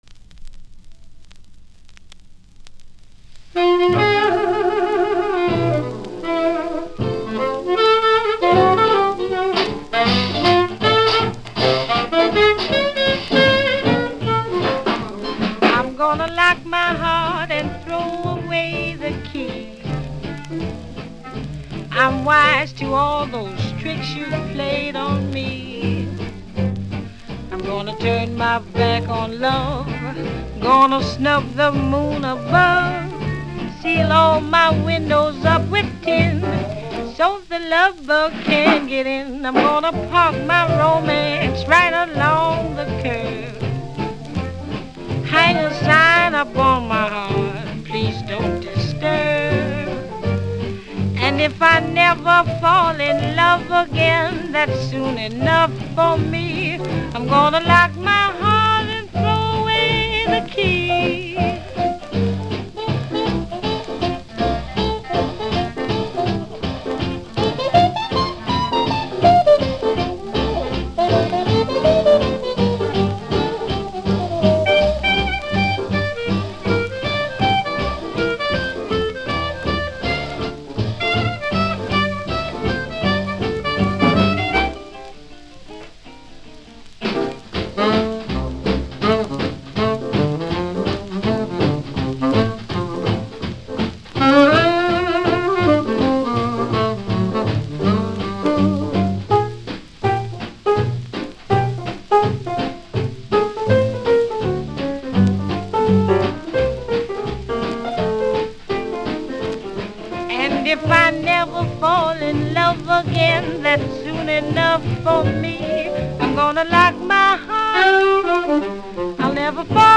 Original LO-FI take
LP моно ремастеринг